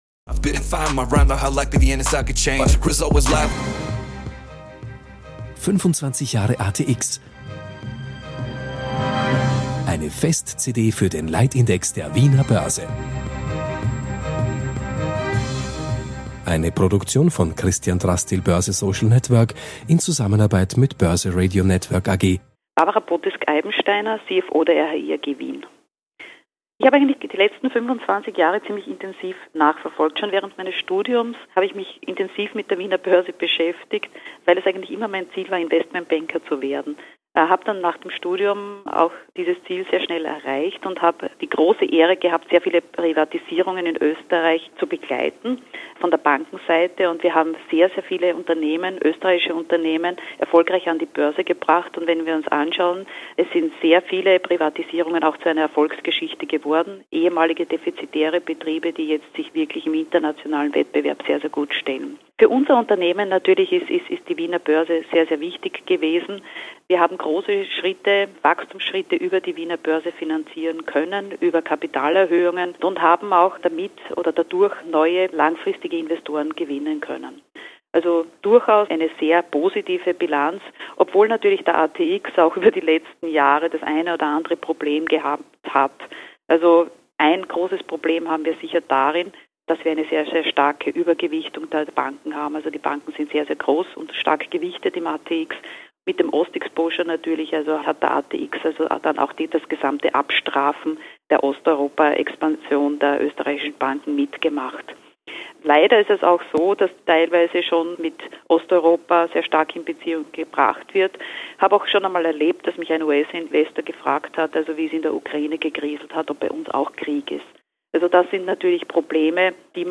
Der ATX wurde dieser Tage 35. Rund um "25 Jahre ATX" haben wir im Dezember 2015 und Jänner 2016 eine grossangelegte Audioproduktion mit dem Ziel einer Fest-CD gemacht, die auch auf Audible als Hörbuch erschienen ist.